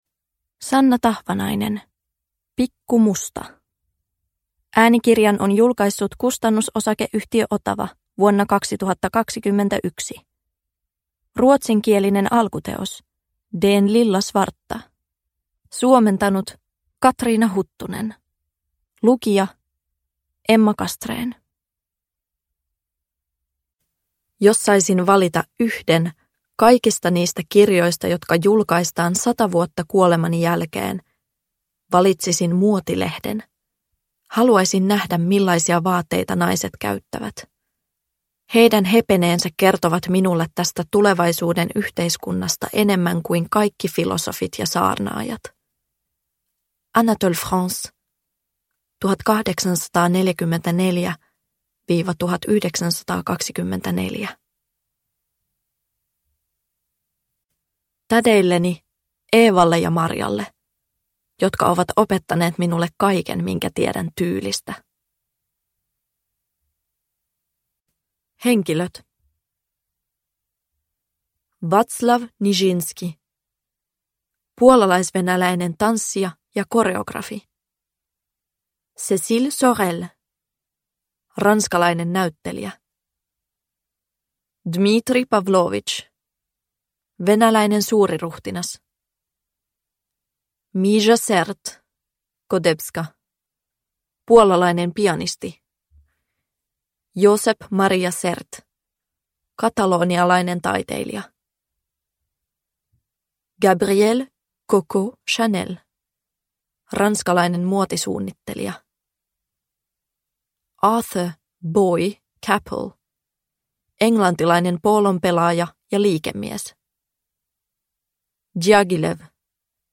Pikkumusta – Ljudbok – Laddas ner